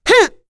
Demia-Vox_Attack8.wav